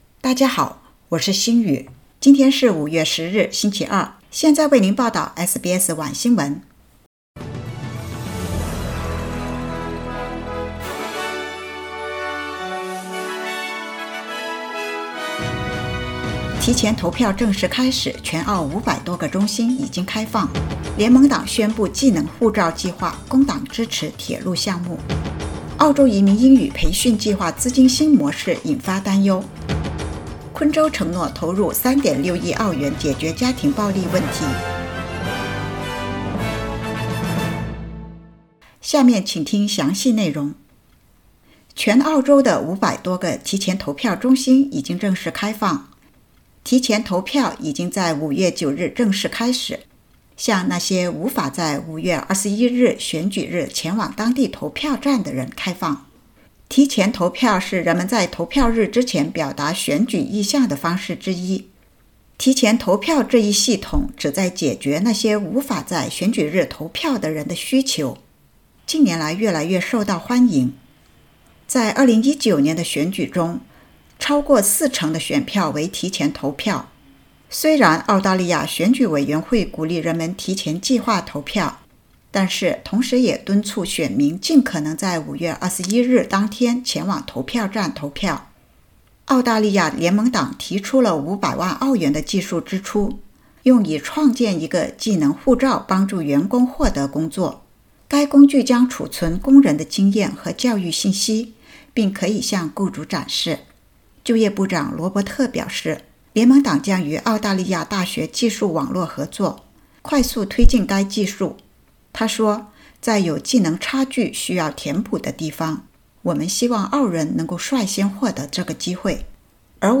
SBS晚新闻（2022年5月10日）
SBS Mandarin evening news Source: Getty Images